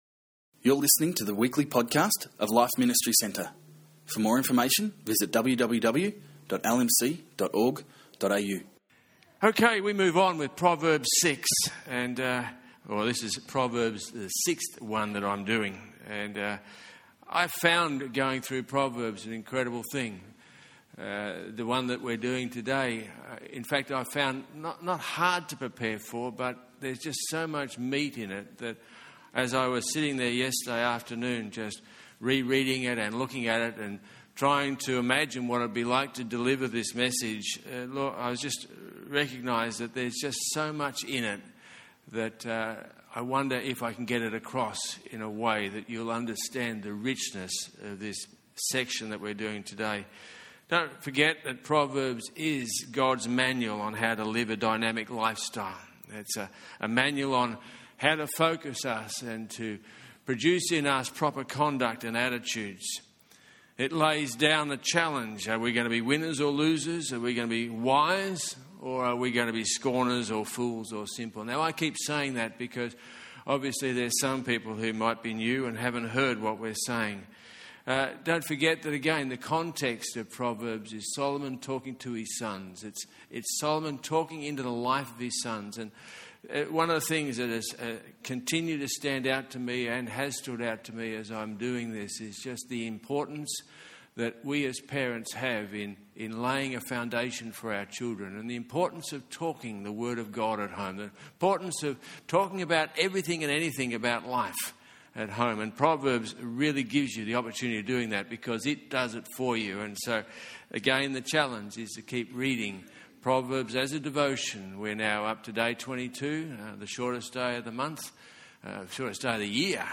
This talk is from chapter 8 and focuses on our friend Wisdom! This message is no. 6 of the series.